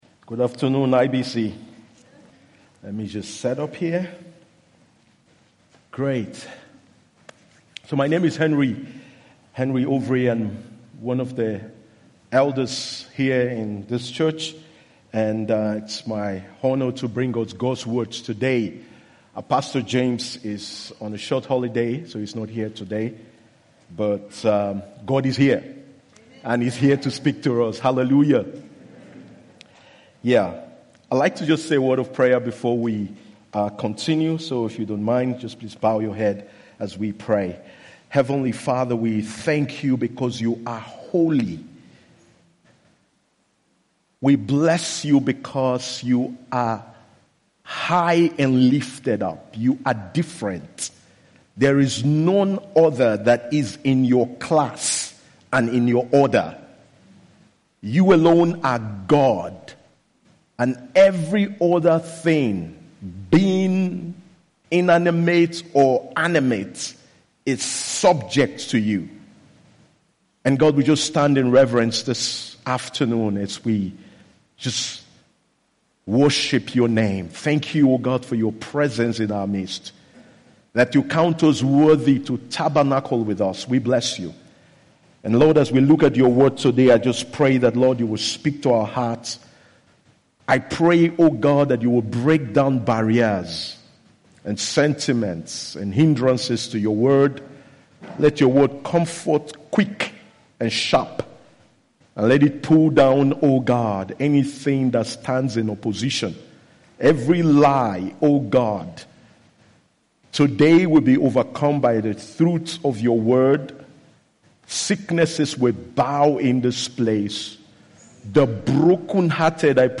IBC Hamburg Predigt